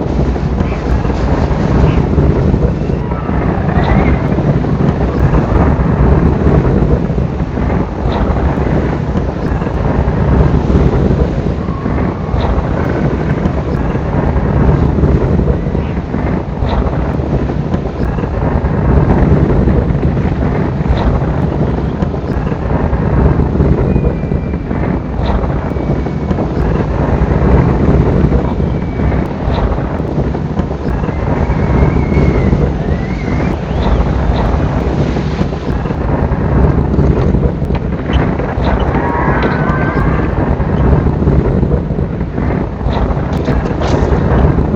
tornadof1.wav